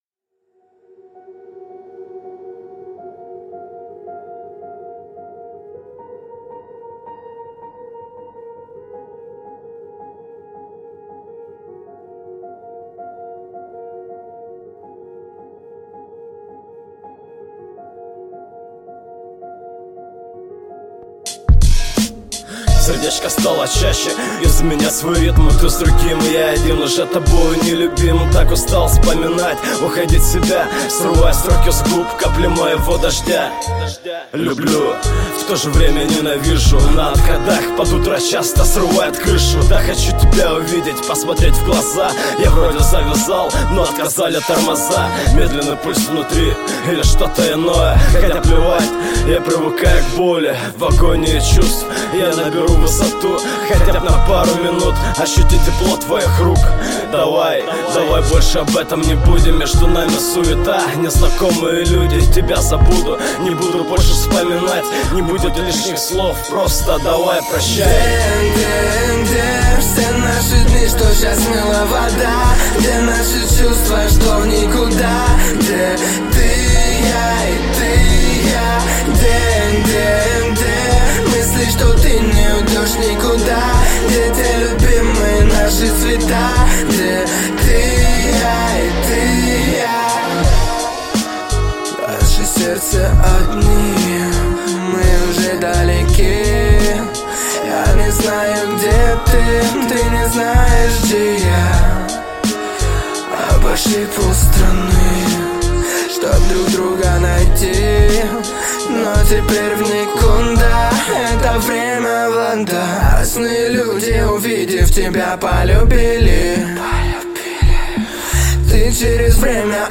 Жанр: Хип-хоп / Русский рэп